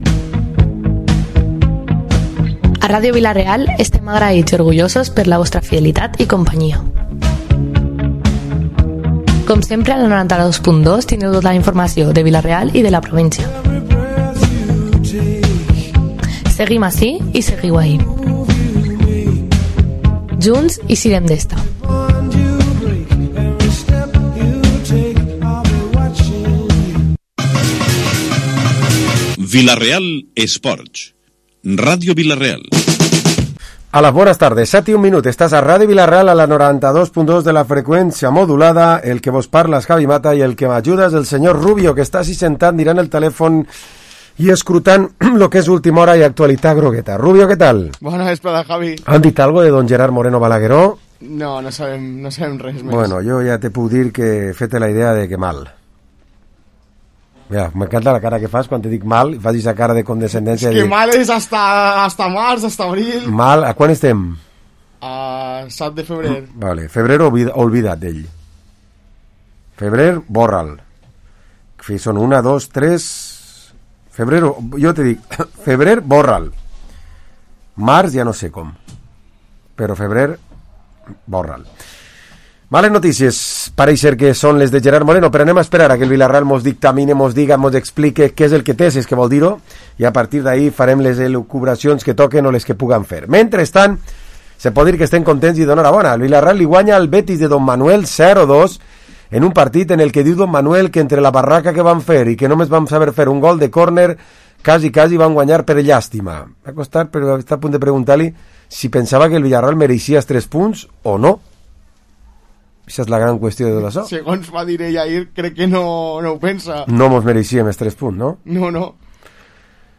Programa esports tertúlia dilluns 7 de Febrer